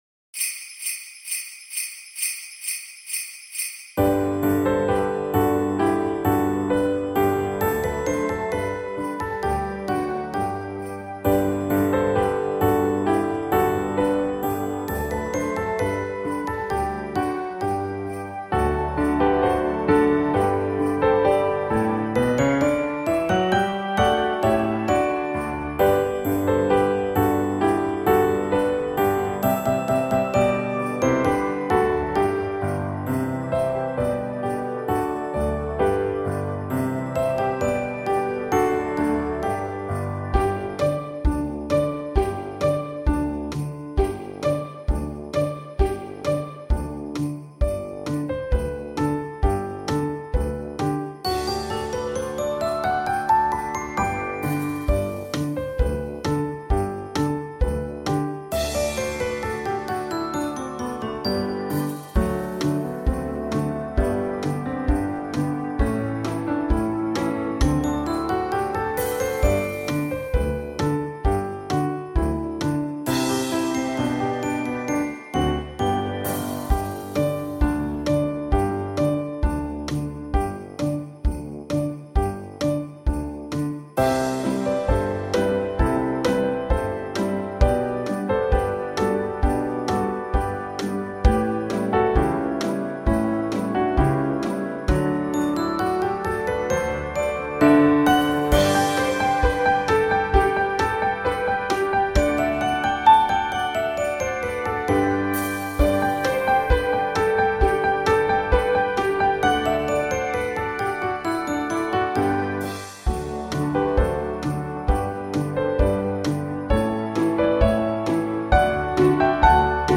(practice)